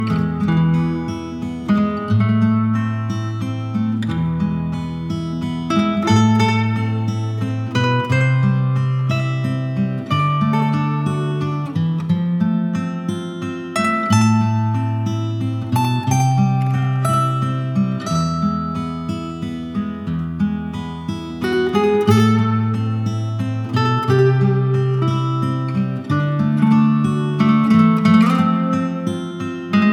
# Instrumental